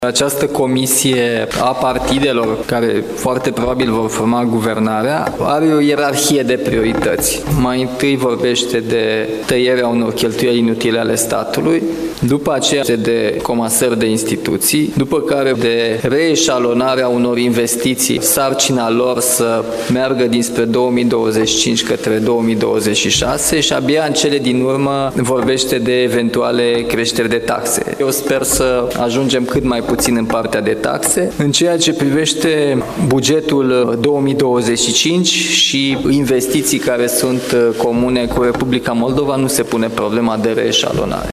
Șeful statului a spus într-o conferință de presă la Chișinău că, totodată, unele investiții vor fi reeșalonate pentru anul viitor.